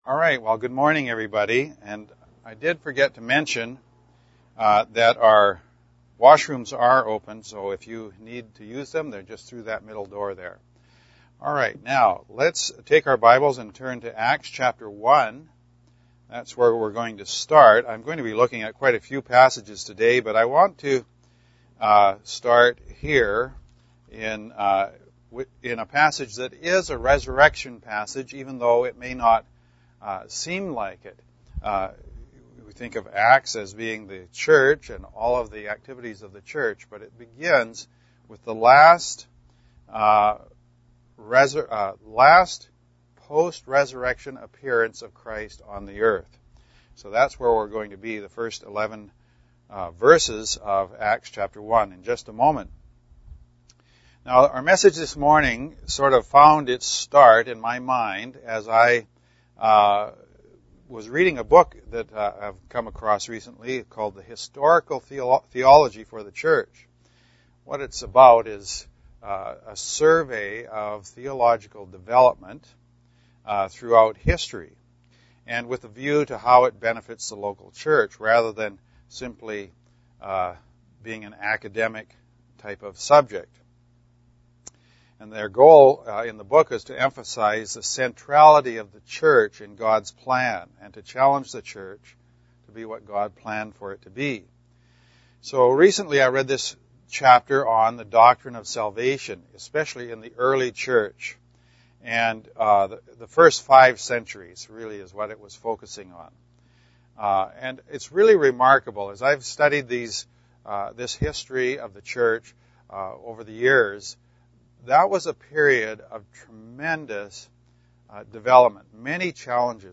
Easter Drive-In Service – Acts 1.11 This Easter, as we think about our Saviour who promised to come again, we consider who it is we expect when he returns.